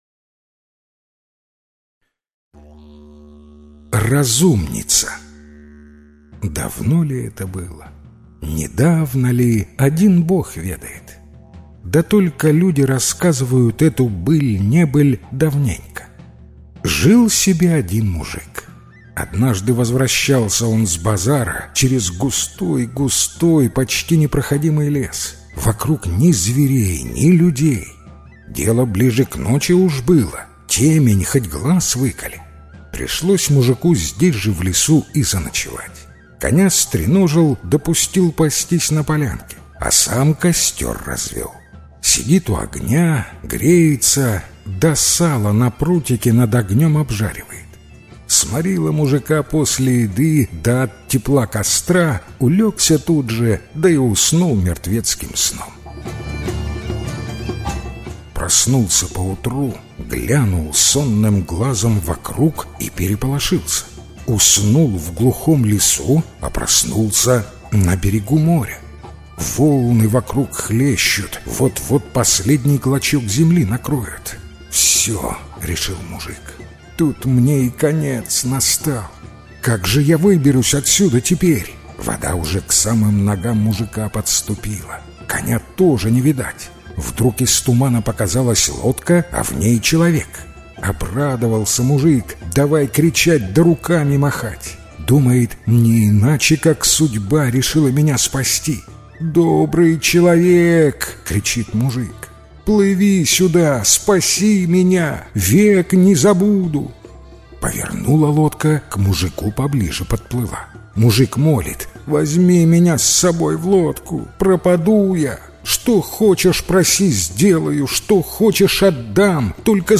Разумница - белорусская аудиосказка - слушать онлайн